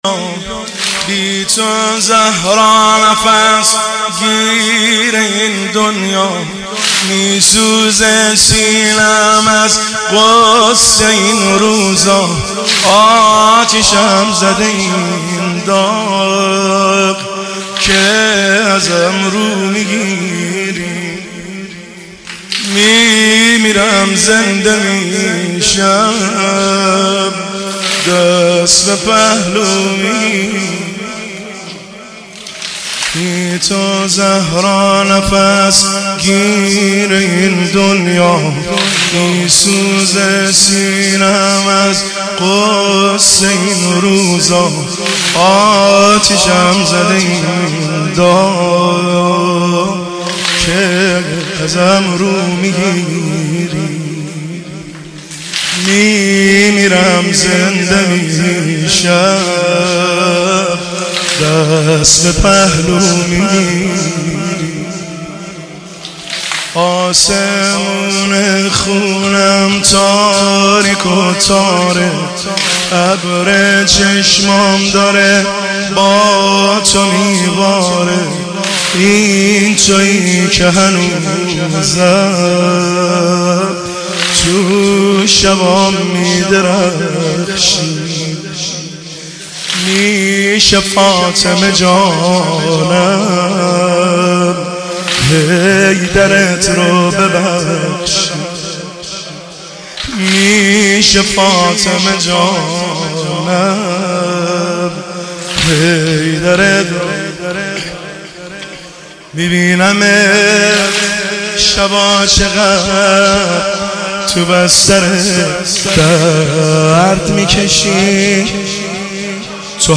بی تو زهرا نفس گیره این دنیا سنگین – شب اول فاطمیه دوم 1402 هیئت بین الحرمین طهران
مداحی